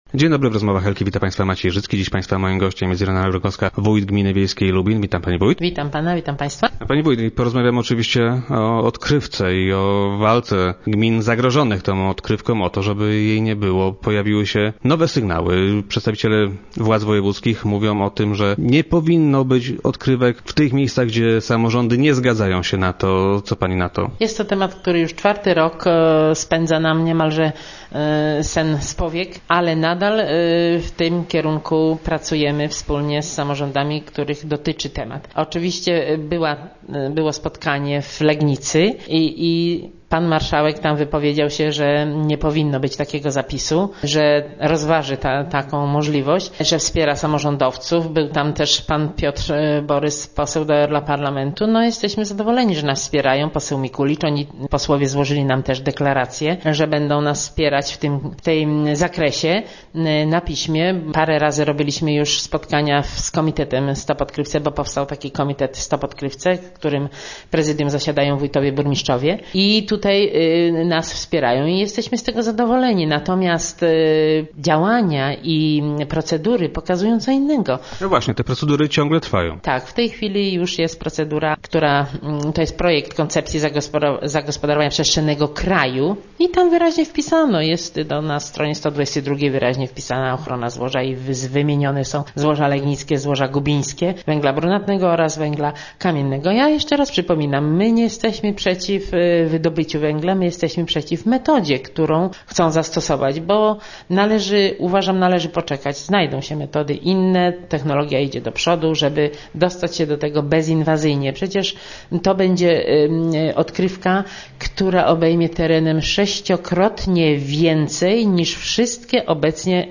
Gościem Rozmów Elki była Irena Rogowska, wójt gminy Lubin.